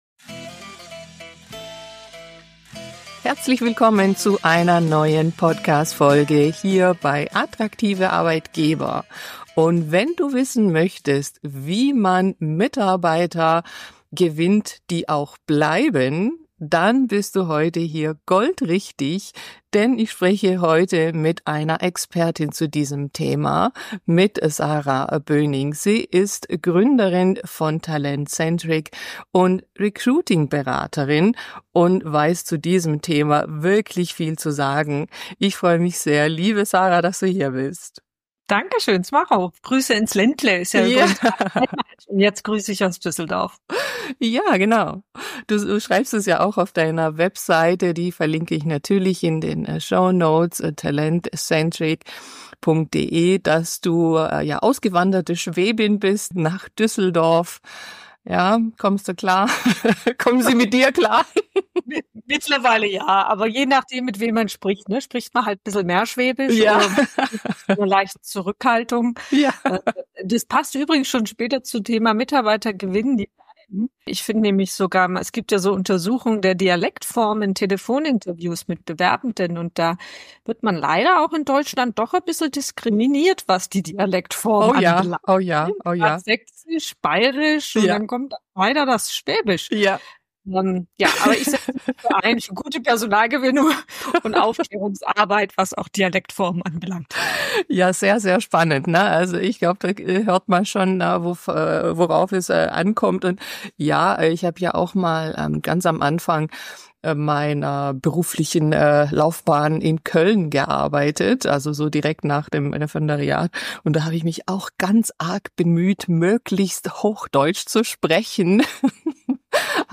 Mitarbeiter gewinnen, die bleiben - Interview